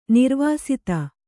♪ nirvāsita